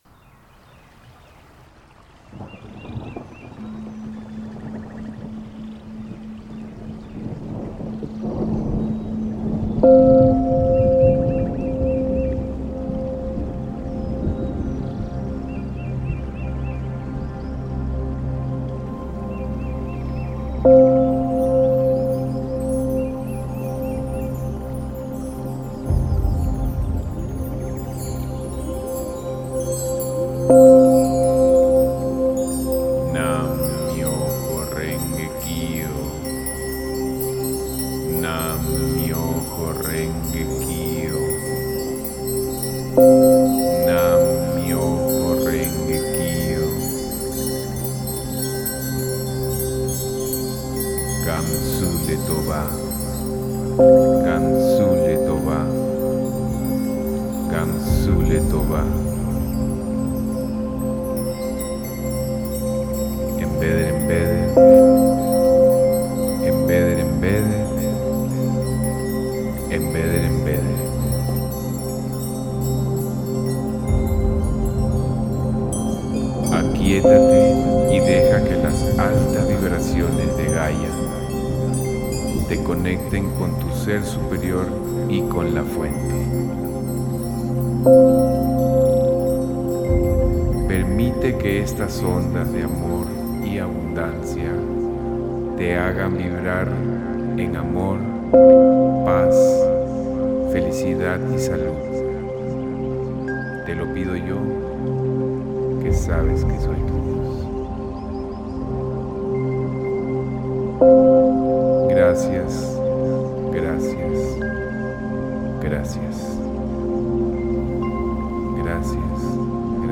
Antiguas campanas y cuencos tibetanos son generadores de altas frecuencias que están asombrosamente sincronizados con las frecuencias de la tierra y de todo el universo. Aquí podrás escuchar los sonidos ancestrales y armonizadores provenientes de estas especiales campanas y cuencos.
Ya que este tema tiene frecuencias muy finas dirigidas específicamente para cada lado del cerebro (sonido de cuencos de cristal binaurales) además tiene las vibraciones de los cuencos y campanas tibetanas. Como si esto fuera poco en el fondo del tema se oye una grabación de alta fidelidad de una tormenta tropical en el medio del amazonas peruano, toda una experiencia sensorial, relajante y sanadora.> Escucha directamente el MP3 haciendo Click Aquí.